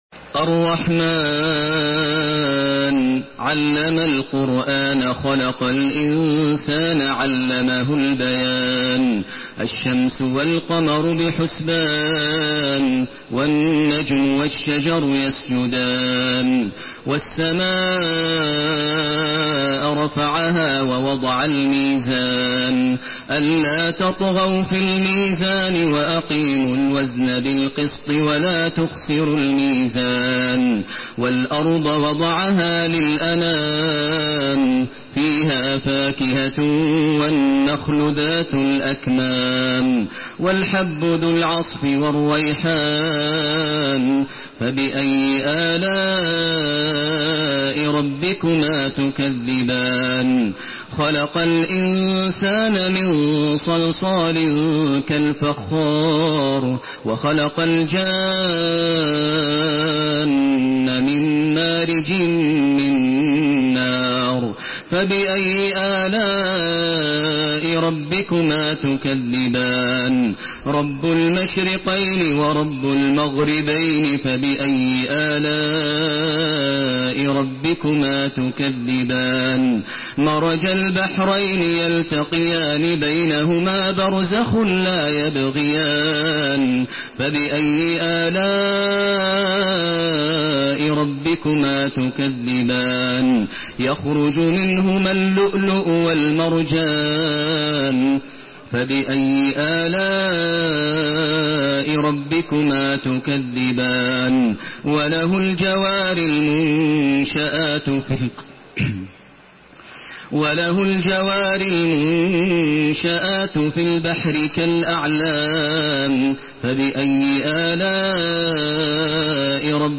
Surah Ar-Rahman MP3 in the Voice of Makkah Taraweeh 1432 in Hafs Narration
Listen and download the full recitation in MP3 format via direct and fast links in multiple qualities to your mobile phone.
Murattal